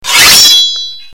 Sword Fight Sound Effect ringtone free download
Sound Effects